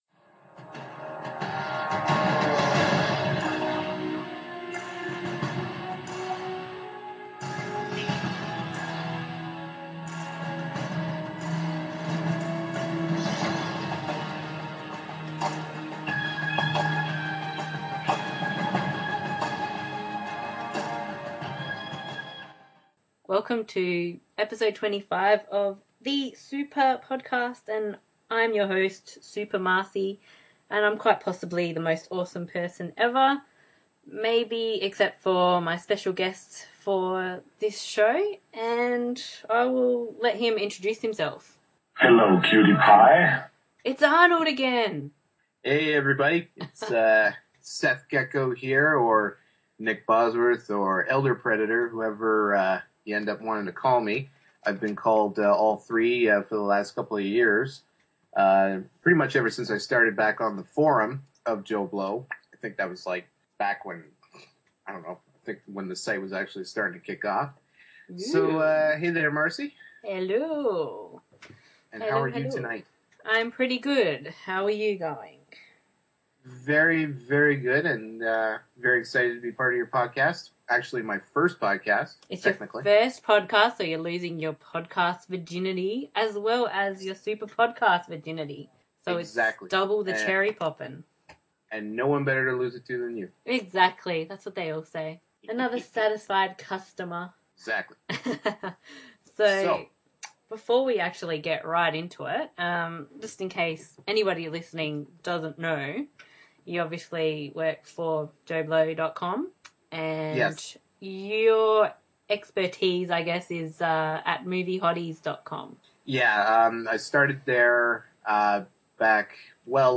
We have some fun with our sound boards, maybe we went a little over board, ehehe. We discuss some of our favourite film heroines, can you guess which ones we picked?